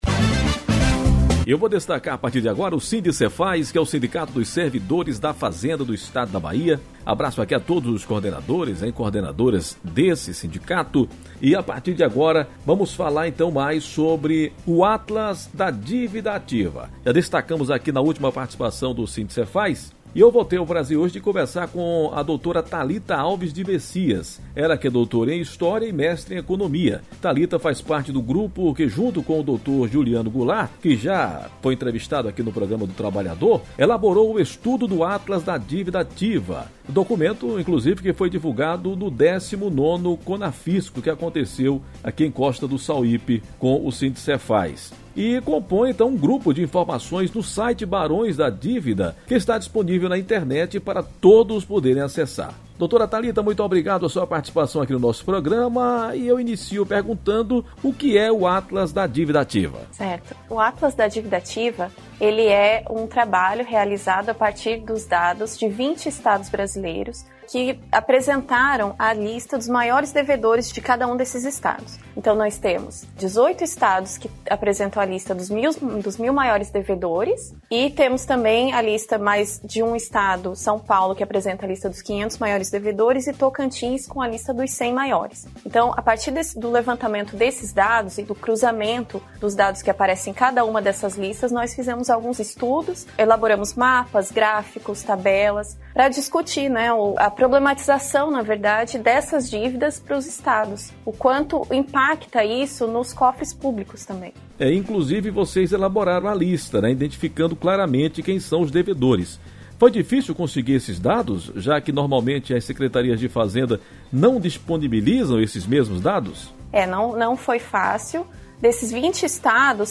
O 19° Conafisco trouxe mais luz às dívidas das empresas para com os estados. Em entrevista à Rádio Metrópole, no sábado (17), uma das autoras do estudo, falou sobre o tema.